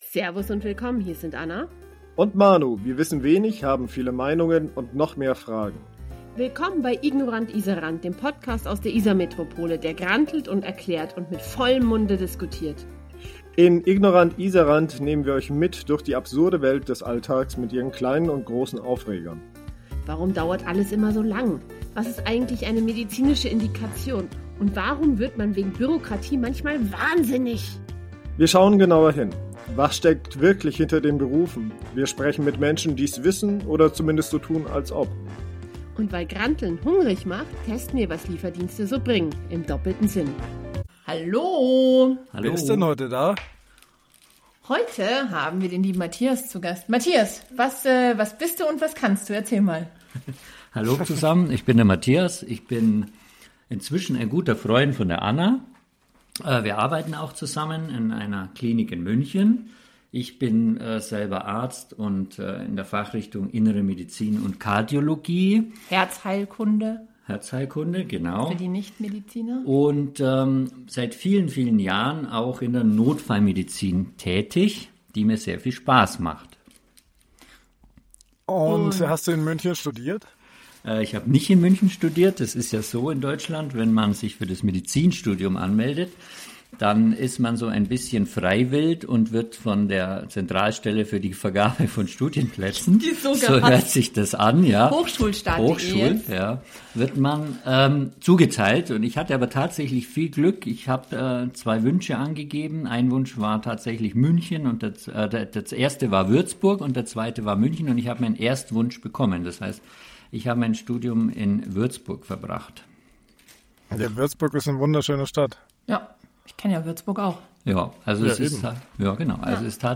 Notruf oder Nervruf? – Interview mit einem Kardiologen und Notarzt Teil 1 ~ Ignorant Isarrant Podcast
In dieser zweiteiligen Interviewfolge sprechen wir mit einem Notarzt über das, was passiert, bevor Patient:innen im Krankenhaus ankommen – über die präklinische Realität im Rettungsdienst.